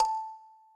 kalimba_a.ogg